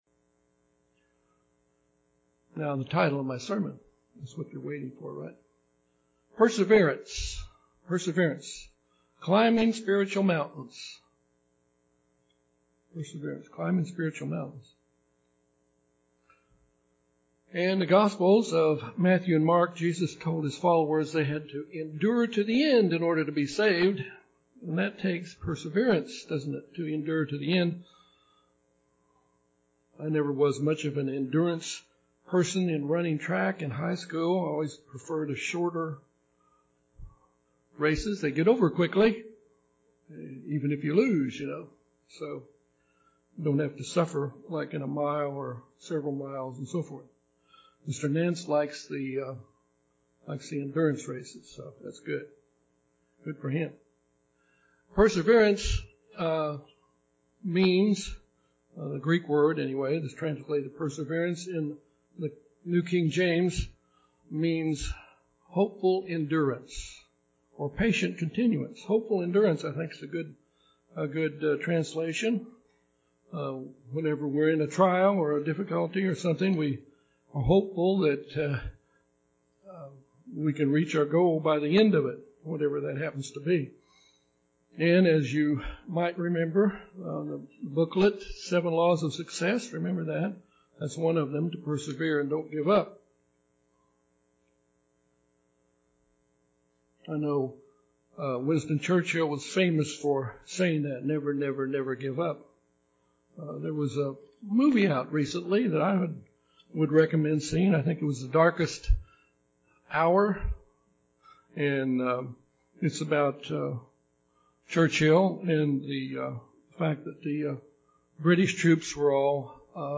People must exhibit perseverance when climbing mountains and likewise we must persevere through the trials that seem like spiritual mountains we face from time to time. This sermon examines four examples of men who persevered through difficult trials, Noah, Jacob, Mark and Jesus.